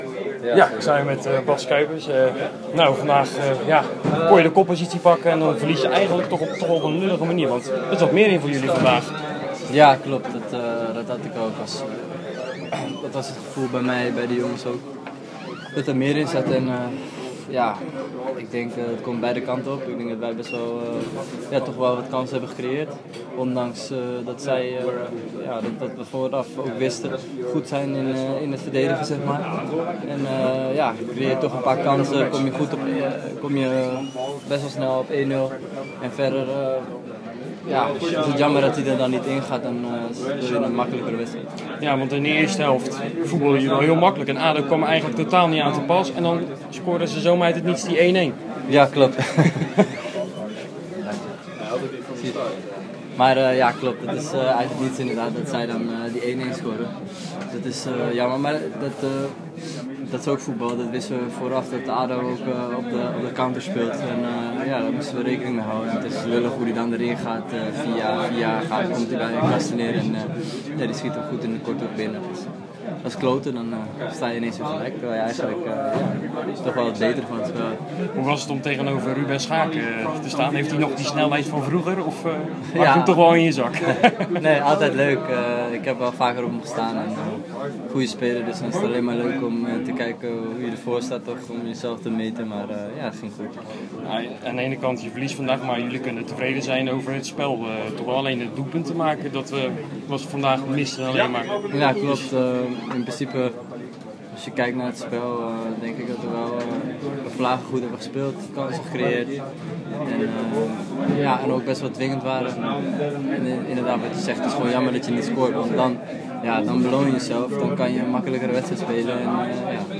na afloop in gesprek